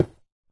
Sound / Minecraft / dig / stone3